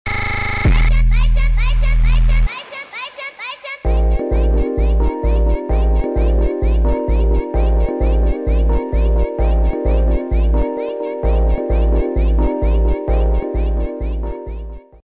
(Super Slowed)